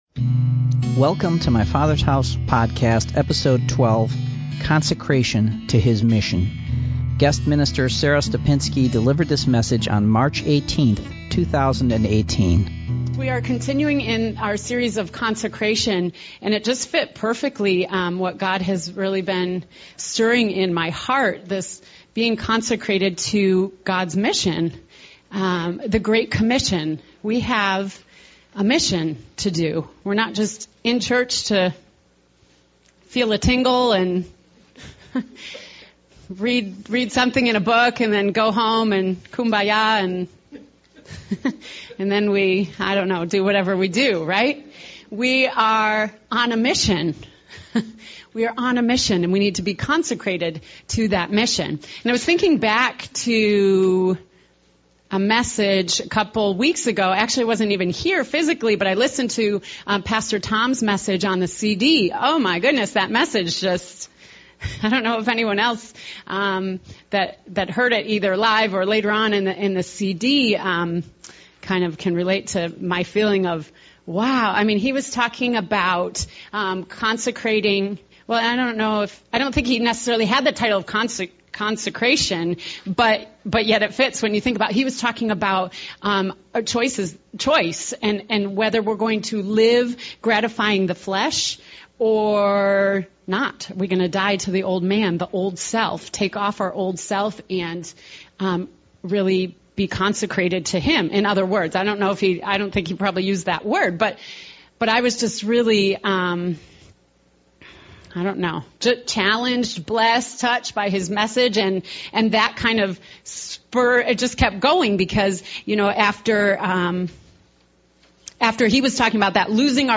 Guest minister